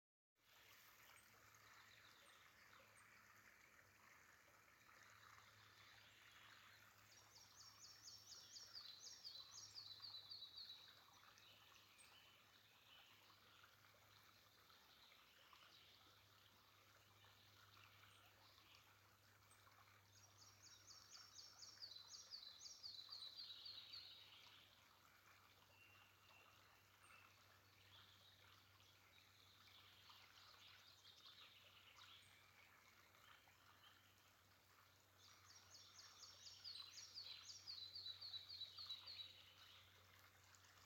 малая мухоловка, Ficedula parva
Administratīvā teritorijaPārgaujas novads
СтатусПоёт